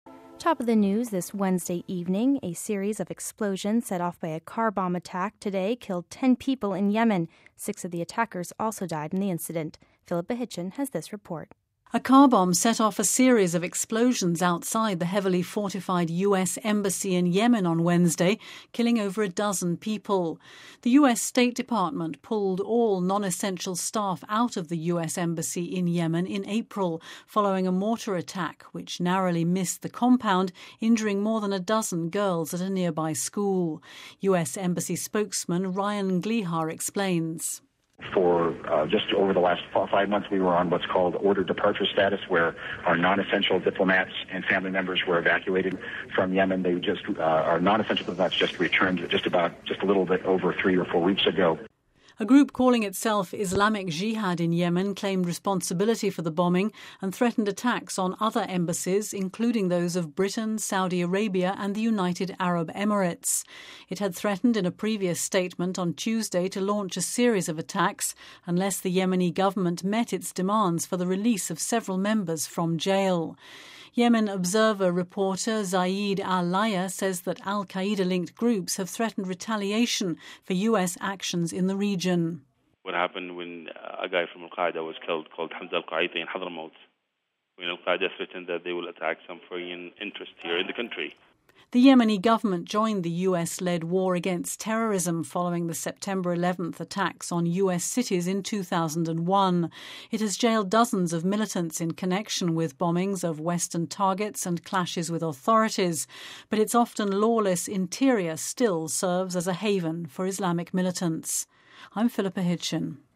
We have this report...